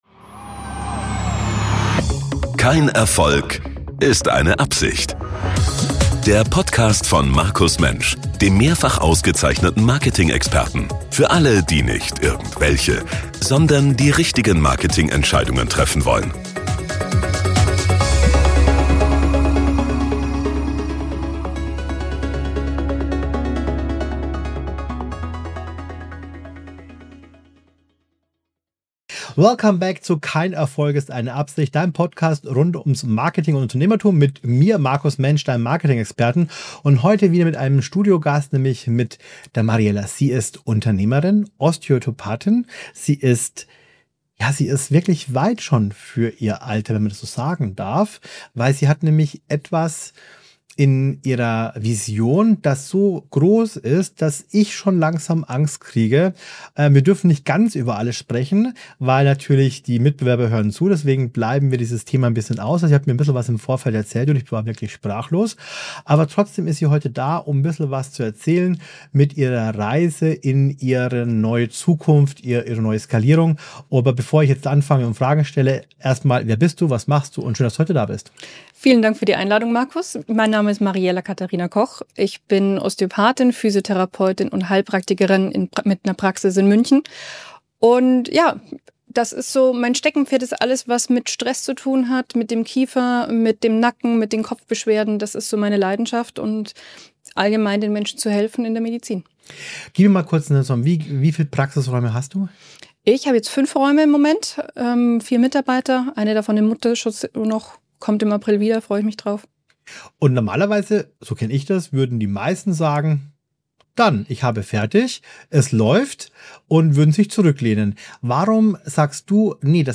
Interview ~ Kein Erfolg ist eine Absicht Podcast